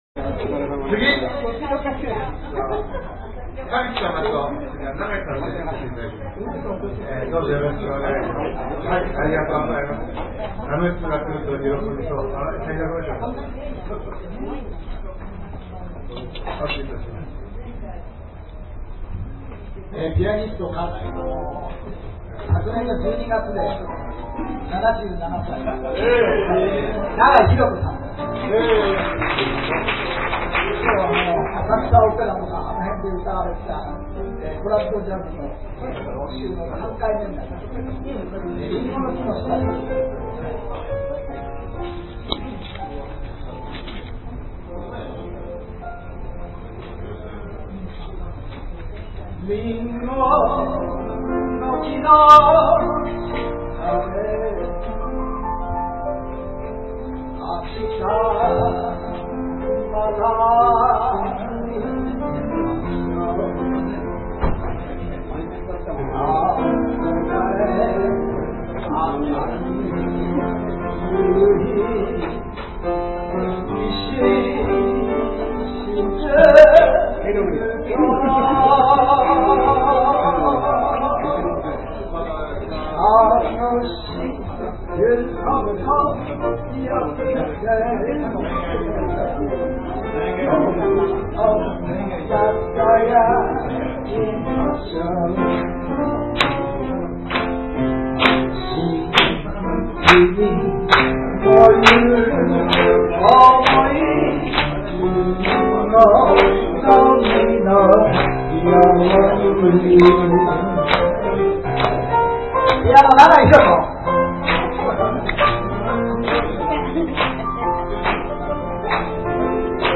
ミュージック・レストラン
「アルテリーベ」   ピアノ伴奏での歌唱 「りんごの木の下で」